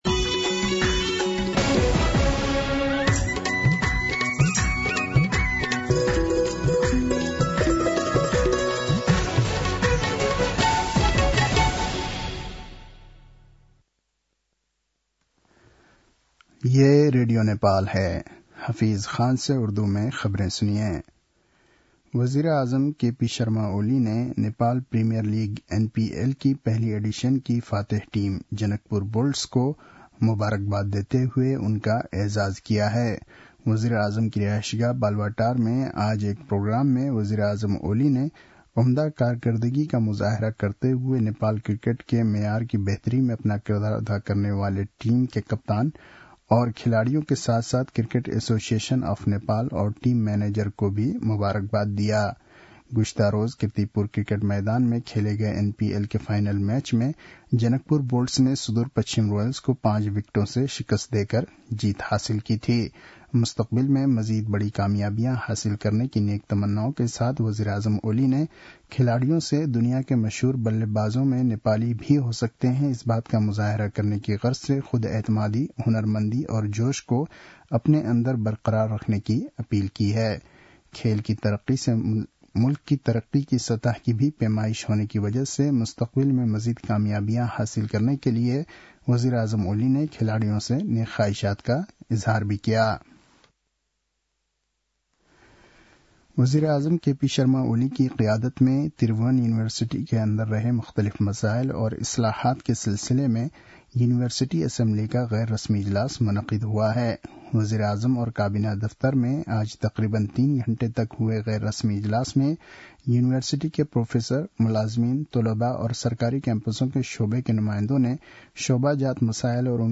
उर्दु भाषामा समाचार : ८ पुष , २०८१
Urdu-News-9-7.mp3